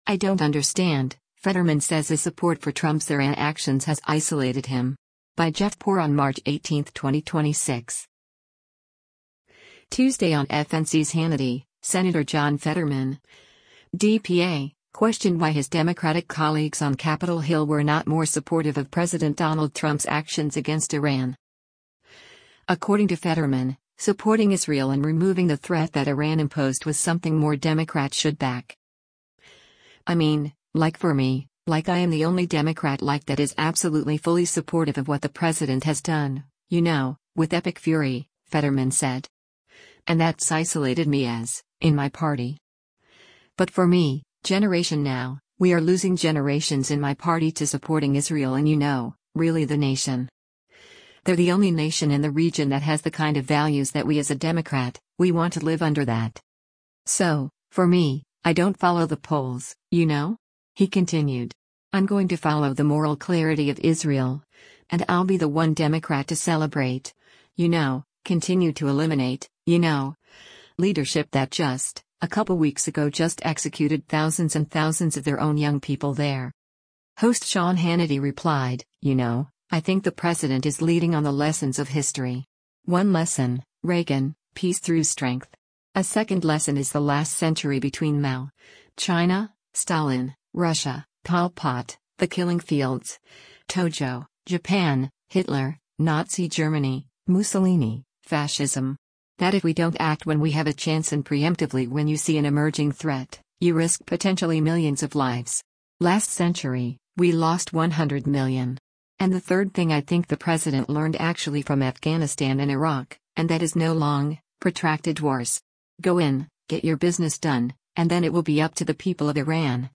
Tuesday on FNC’s “Hannity,” Sen. John Fetterman (D-PA) questioned why his Democratic colleagues on Capitol Hill were not more supportive of President Donald Trump’s actions against Iran.